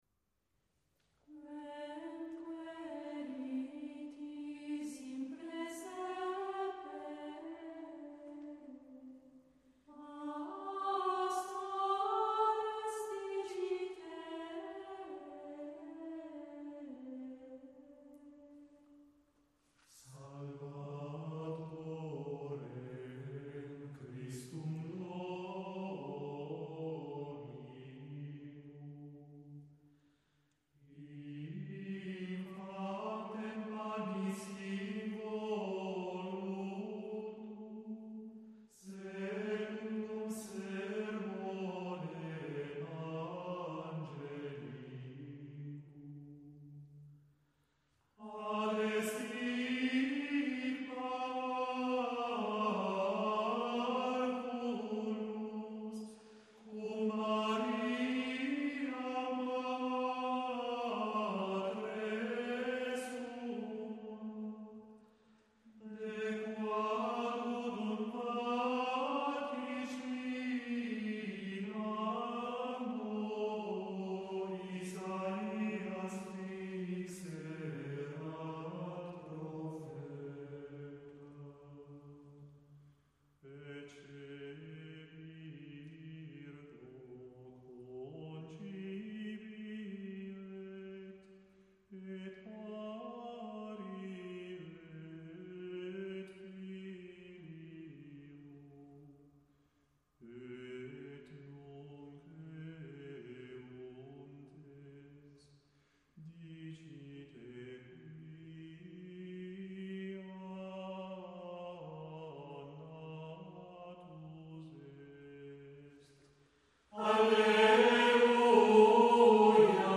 Dobbiamo tentare di immaginare la suggestione di questa liturgia drammatica, celebrata col canto gregoriano e moderata gestualità, nelle austere chiese preromaniche illuminate dalle torce, l'emozione che commuoveva i fedeli, molti dei quali erano discendenti dei barbari recentemente convertiti, che, nell'ignoranza assoluta del latino ecclesiastico, potevano finalmente, grazie all'Ufficio drammatico, partecipare consapevolmente alla liturgia della Resurrezione.